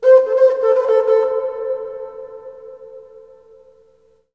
REBKHorn07.wav